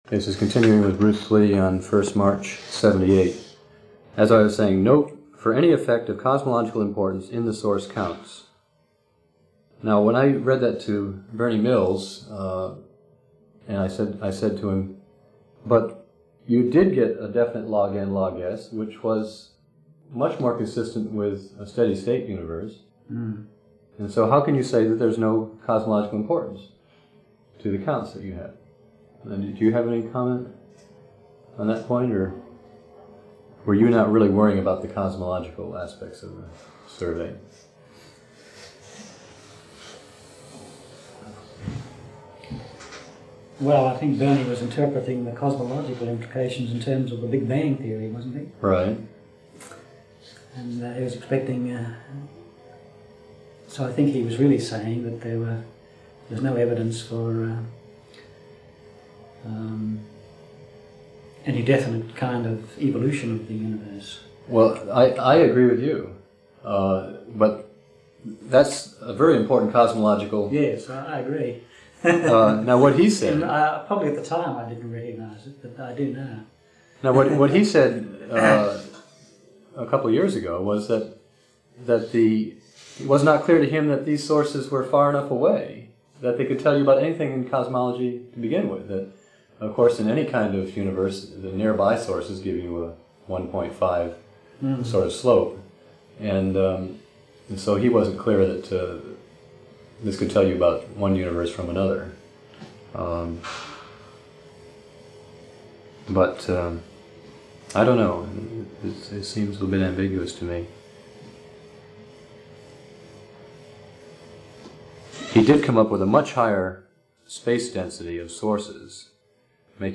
We are grateful for the 2011 Herbert C. Pollock Award from Dudley Observatory which funded digitization of the original cassette tapes, and for a 2012 grant from American Institute of Physics, Center for the History of Physics, which funded the work of posting these interviews to the Web.